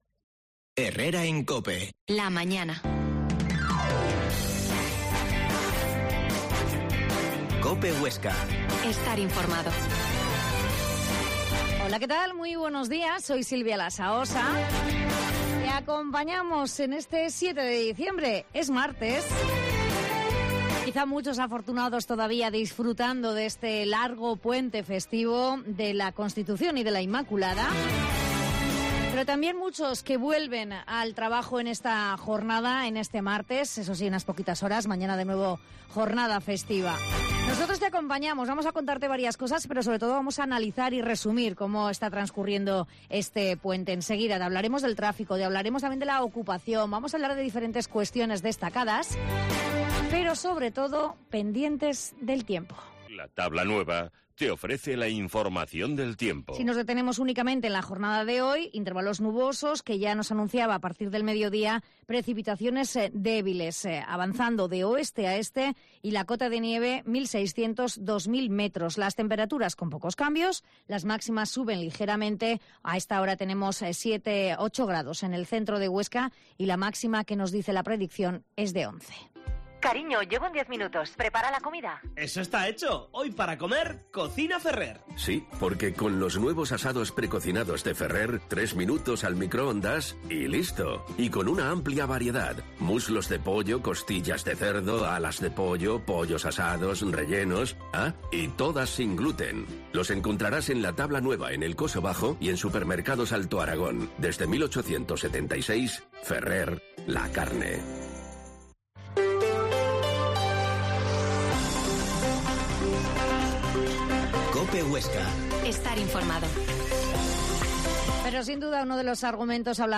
Herrera en COPE Huesca 12.50h Entrevista a la jefa provincial de tráfico, Margarita Padial